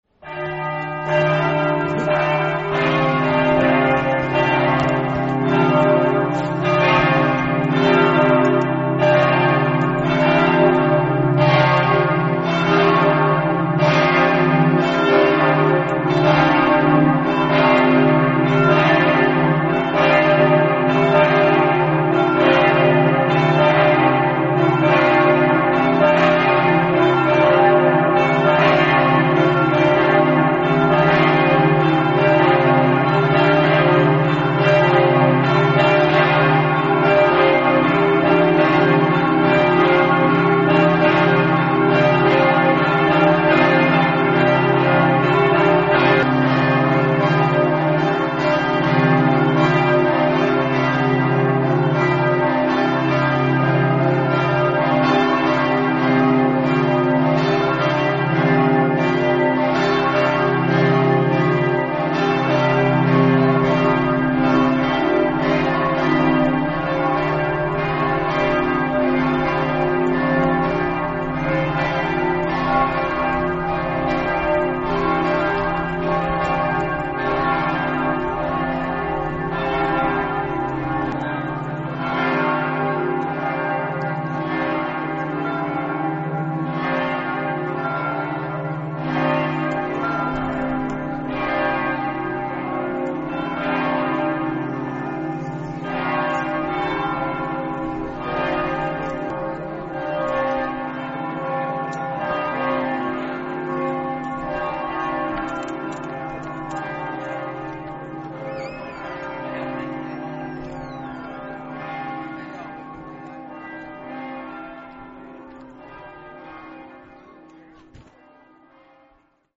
Cividale del Friuli (UD), 05 Maggio 2024
CAMPANE DEL DUOMO